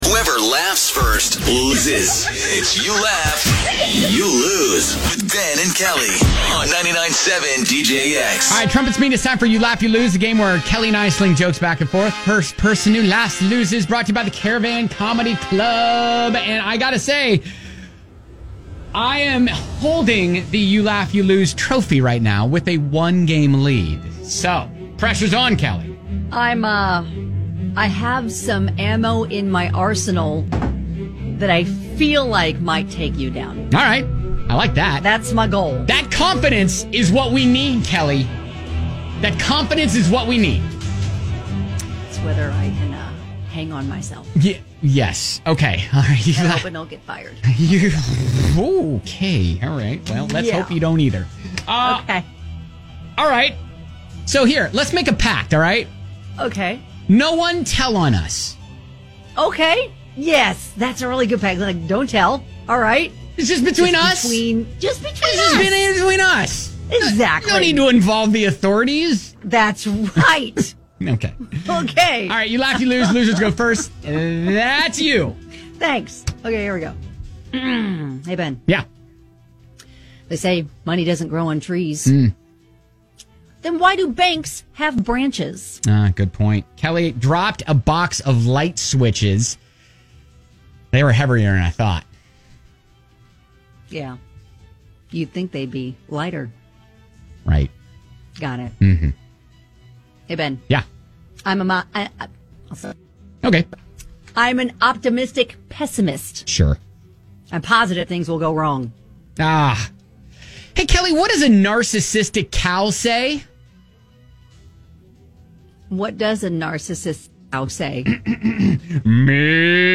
toss jokes back and forth until someone laughs